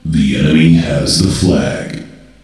voc_enemy_1flag.ogg